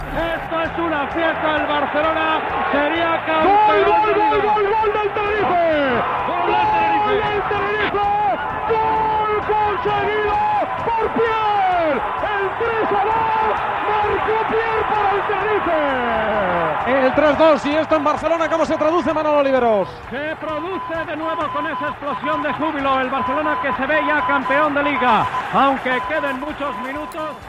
Transmissió de l'última jornada de la lliga masculina de futbol professional des dels camps del Tenerife i el Futbol Club Barcelona.
Esportiu